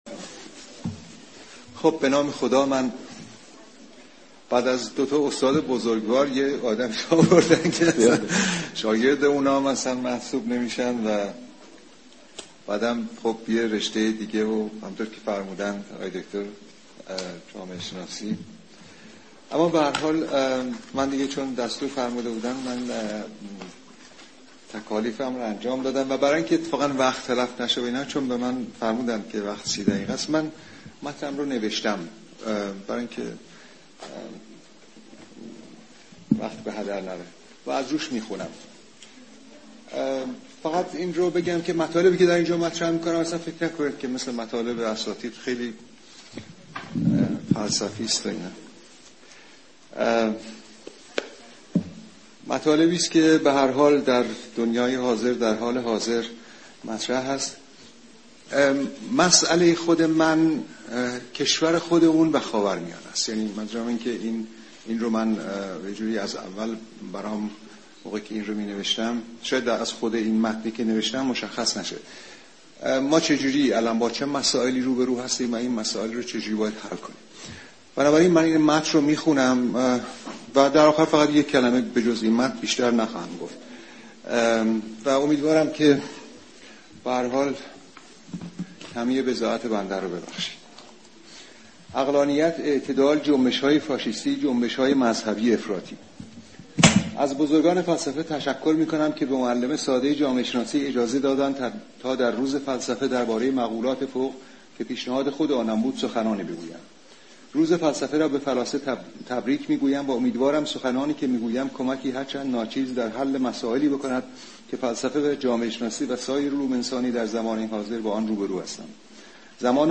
فایل زیر، سخنرانی یوسف اباذری در نشست روز جهانی فلسفه (۲۴ آبان) است که در موسسه پژوهشی حکمت و فلسفه ایران برگزار شد.